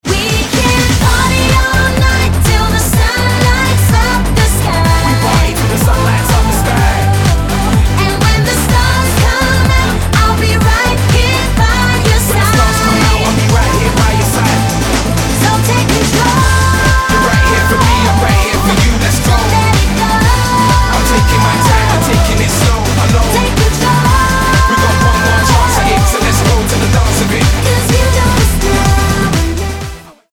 naspievala skladbu s anglickou speváčkou